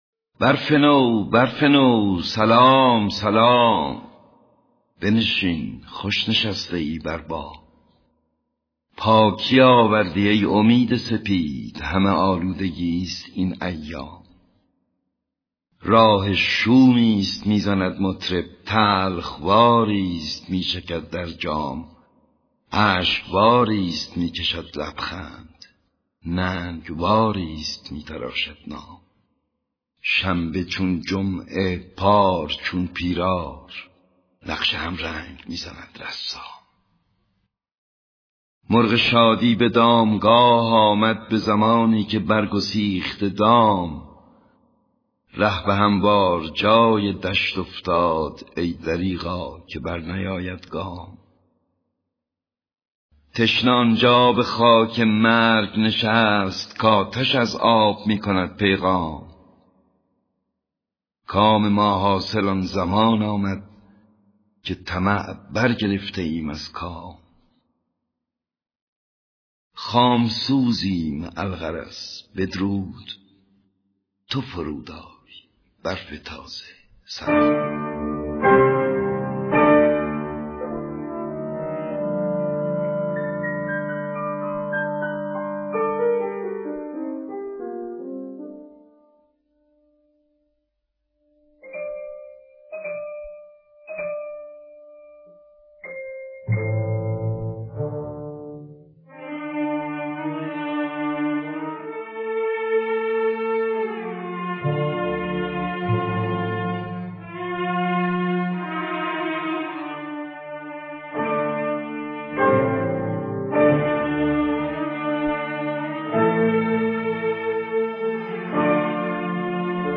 Published in دکلمه and شعر